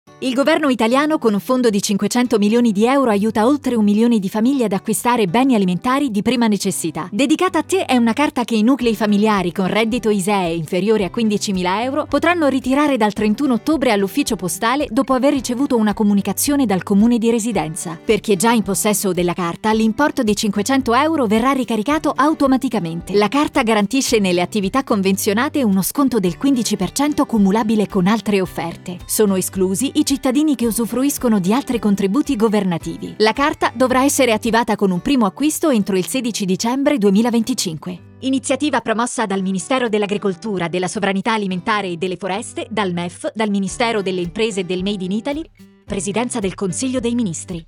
Lo spot video